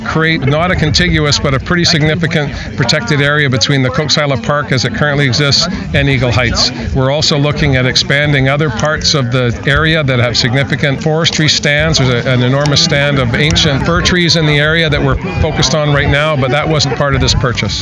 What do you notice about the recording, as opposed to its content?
The Premier was in Shawnigan Lake earlier today to make an announcement.